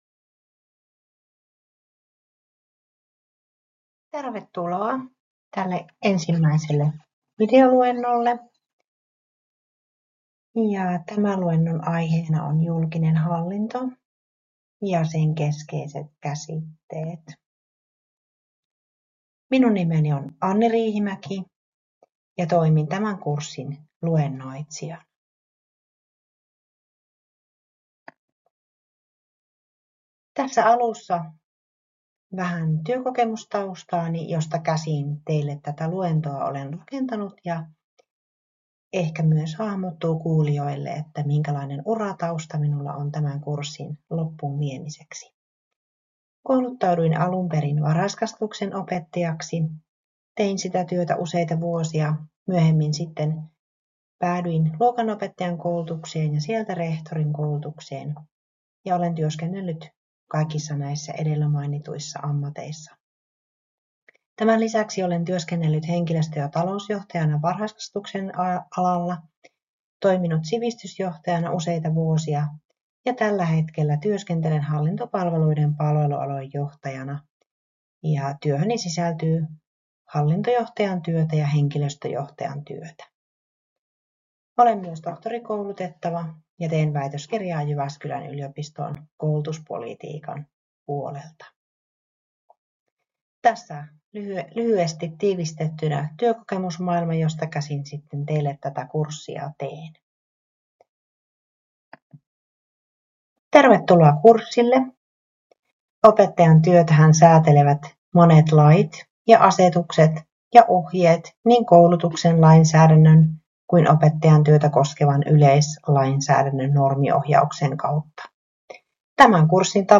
KTK0006 Opetushallinnon luentotallenne